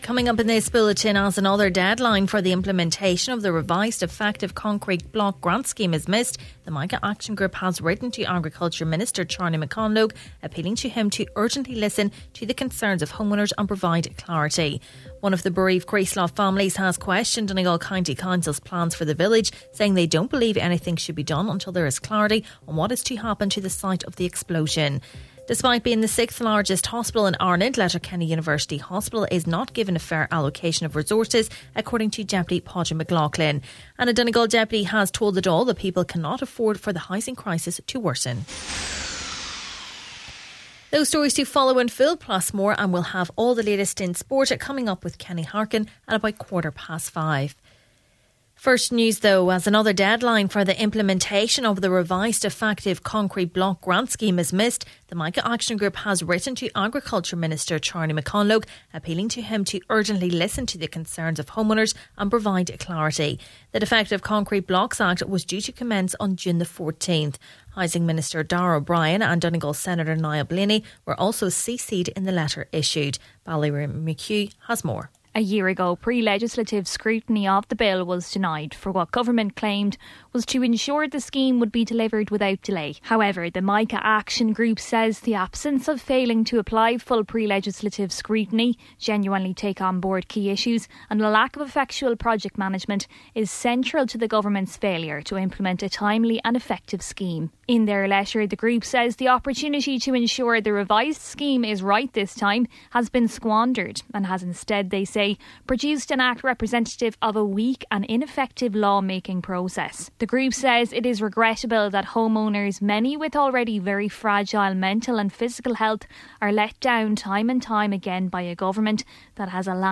Main Evening News, Sport, Farming News and Obituaries – Thursday June 22nd